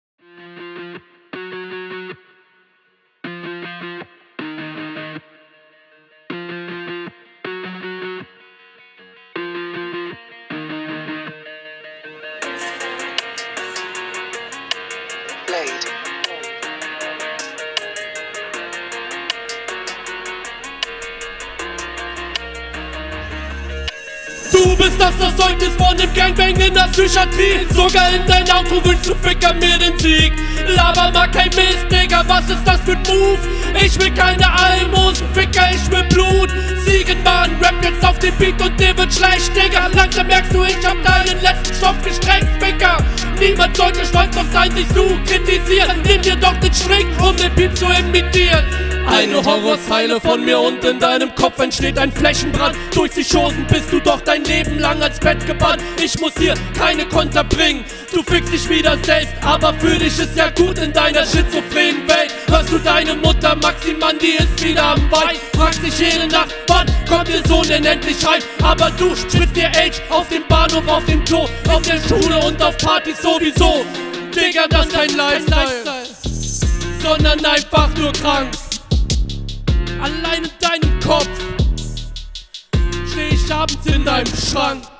Flow: stabiler flow aber leider ein paar unsaubere doubles Text: inhaltlich stärker aber leider wenige …
Flow: Flow ist routiniert. Sehr gut, kann man aber noch etwas Abwechslung reinbringen. Text: Nice …
Der Stimmeinsatz ist sehr aggressiv.